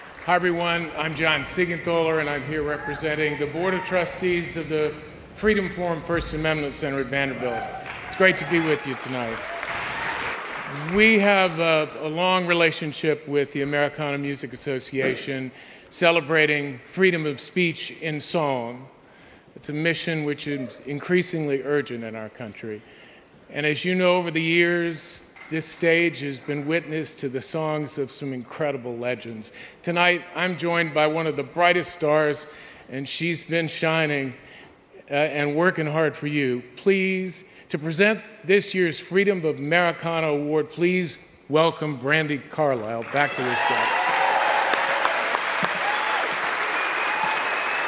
lifeblood: bootlegs: 2022: 2022-09-14: the ryman auditorium - nashville, tennessee (americanafest honors and awards show)
(audio capture from youtube of the presentation of the spirit of americana award for lifetime achievement as songwriters and recording and touring artists)
01. brandi carlile introduction - john seigenthaler, jr. (0:47)